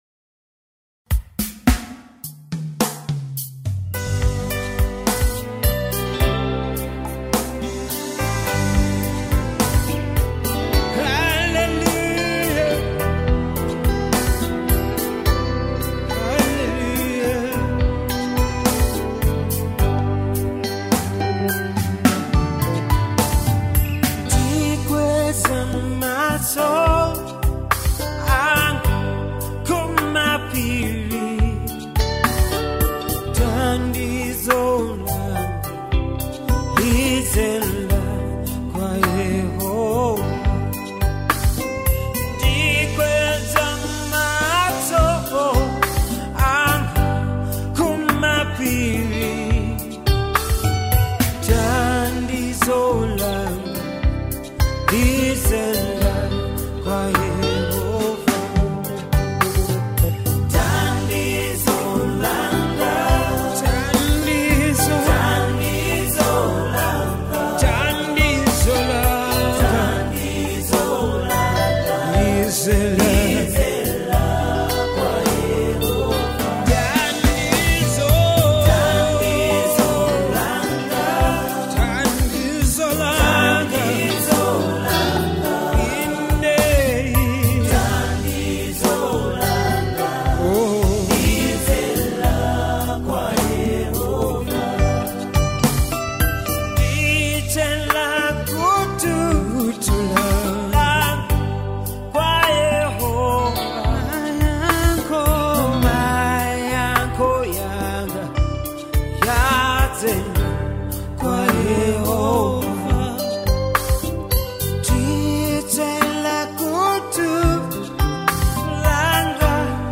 worship anthem
With its inspiring lyrics and melodious arrangement